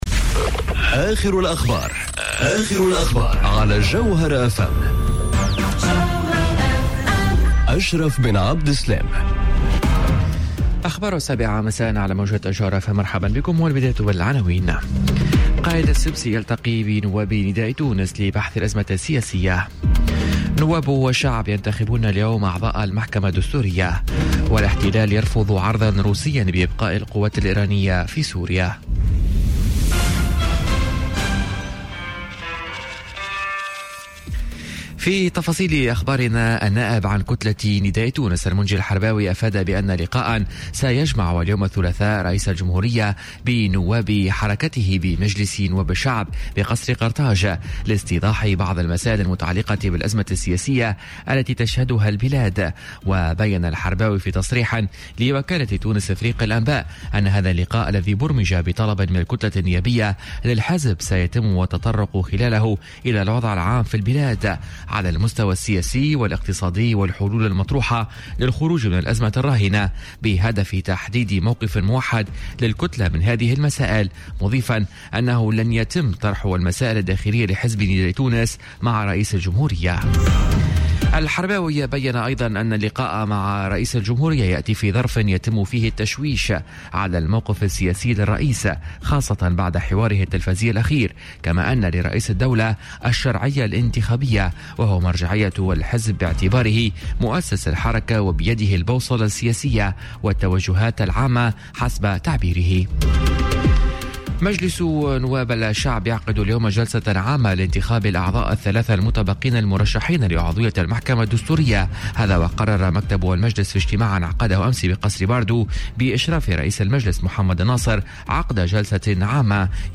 نشرة أخبار السابعة صباحا ليوم الثلاثاء 24 جويلية 2018